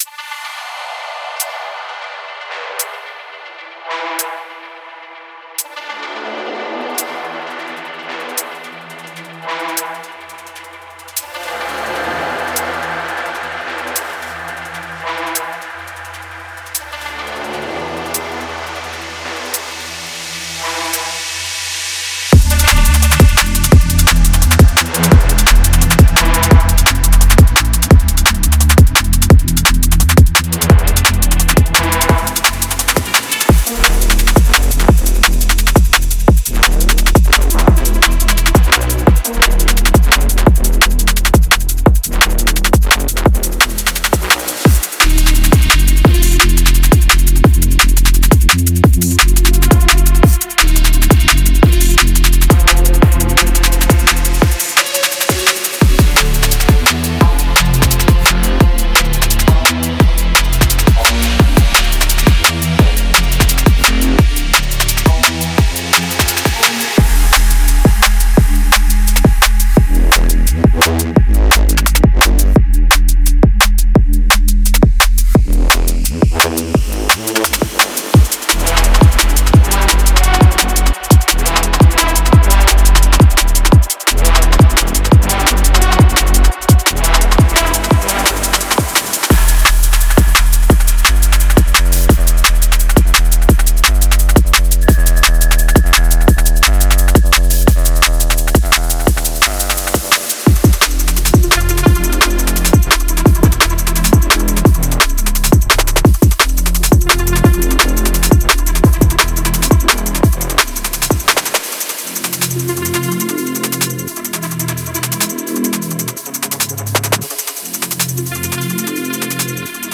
Genre:Drum and Bass
デモトラックで聴こえるドラムおよびFXサウンドは、あくまでイメージを示すためのものです。
デモサウンドはコチラ↓